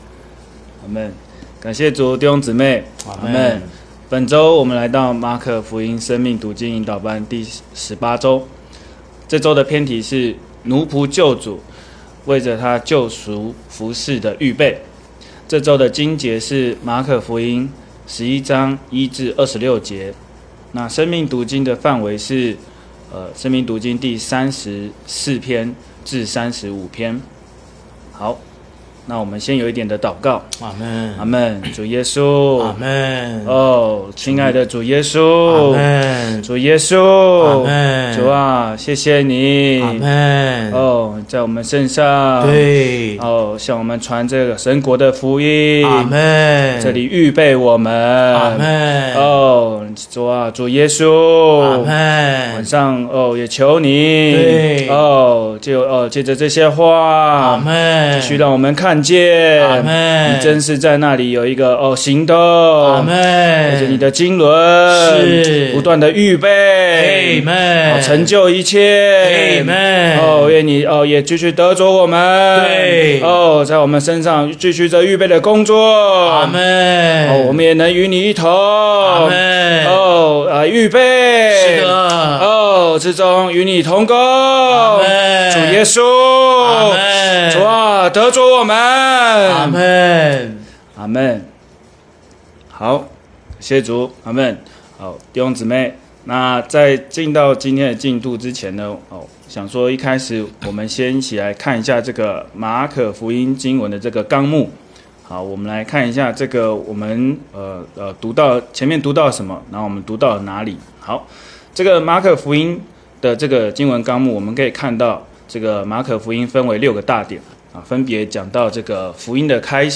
生命讀經引導班